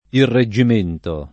vai all'elenco alfabetico delle voci ingrandisci il carattere 100% rimpicciolisci il carattere stampa invia tramite posta elettronica codividi su Facebook irreggimentare v.; irreggimento [ irre JJ im % nto ] — non irregimentare — cfr. reggimento